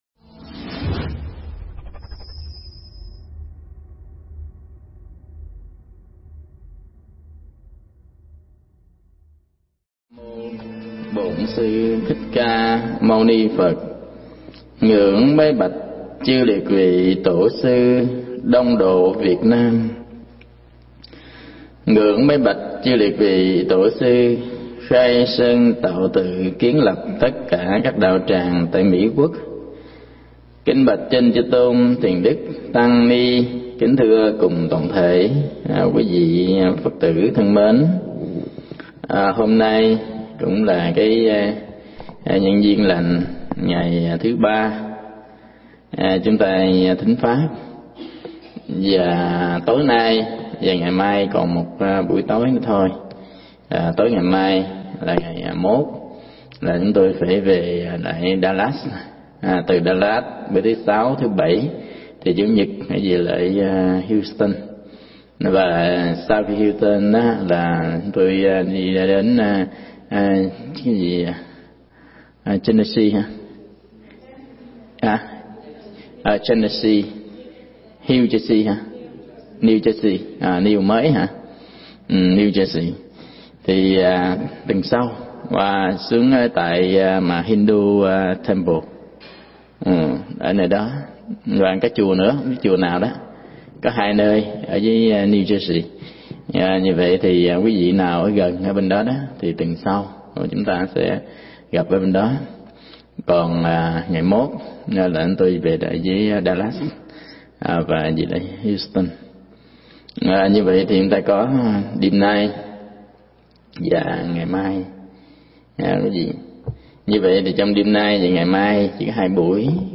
Mp3 Pháp Thoại Tâm nguyện người tu
Chùa Phật Quang, Philadelphia, Mỹ